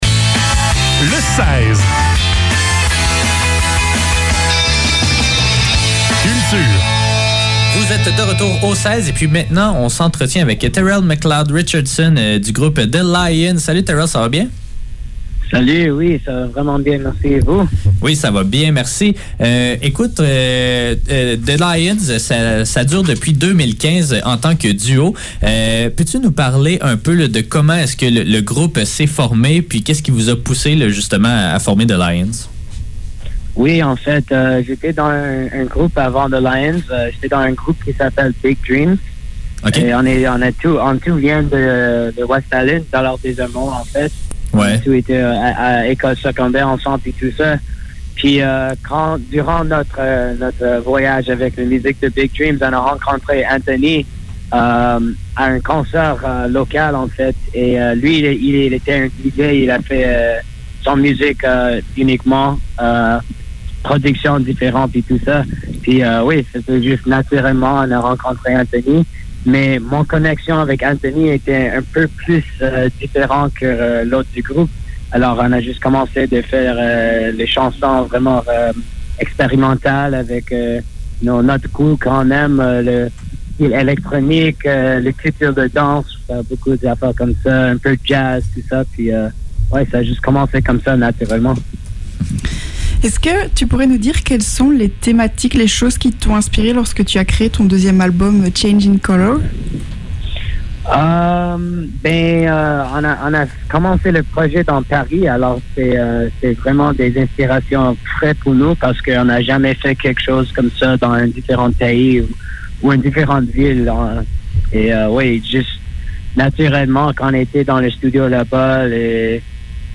Le seize - Entrevue avec The Lyonz - 7 octobre 2021